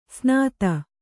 ♪ snāta